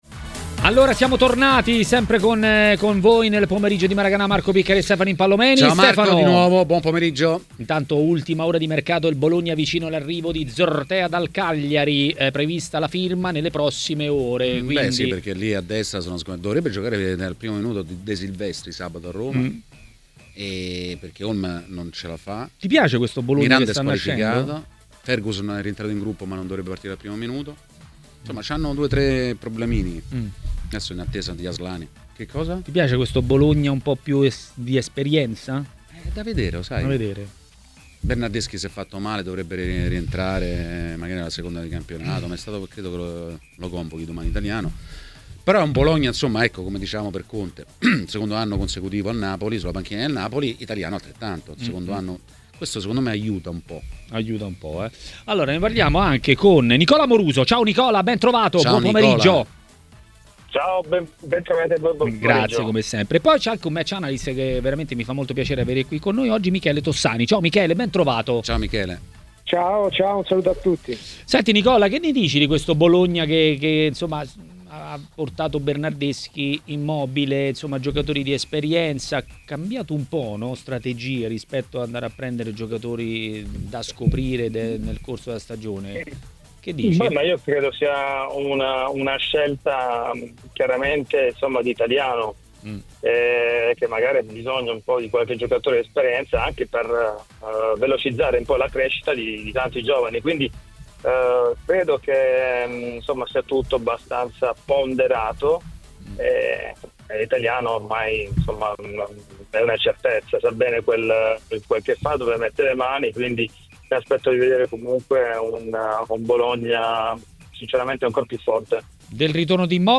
Le Interviste
Durante 'Maracana' sulle frequenze di Tmw Radio si è parlato del tema Romelu Lukaku. Il centravanti belga ha accusato una lesione muscolare di alto grado e rimarrà ai box per almeno tre mesi, con il Napoli che perde il suo riferimento in attacco.
Questo il pensiero dell'ex attaccante di Juventus e Napoli Nicola Amoruso.